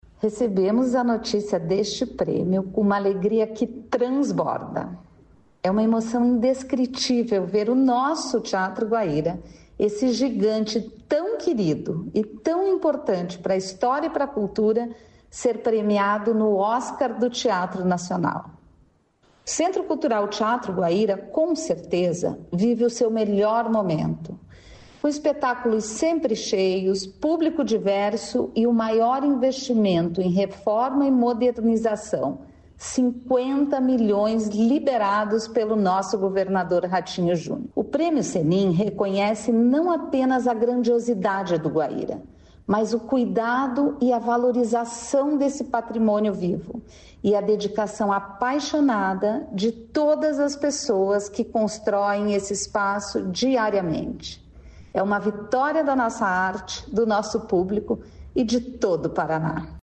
Sonora da secretária de Estado da Cultura, Luciana Casagrande Pereira, sobre a premiação do Teatro Guaíra